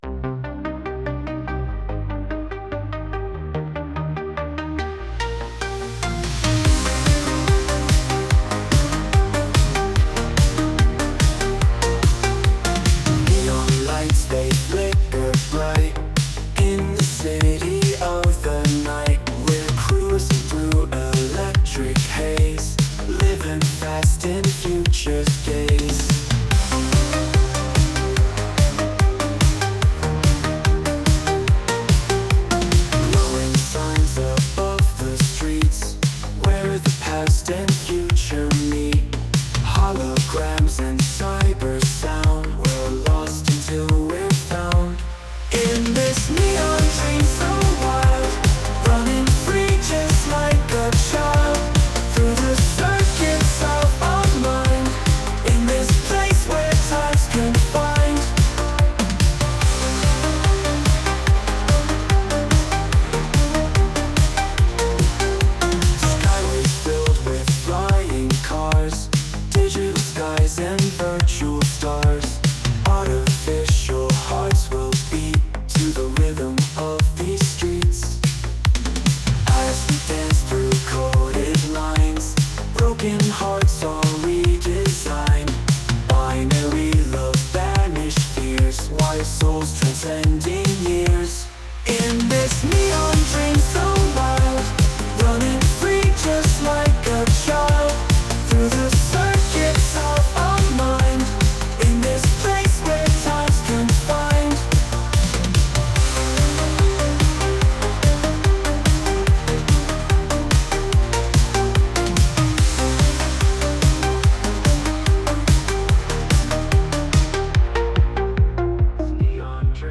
Musique générée par IA.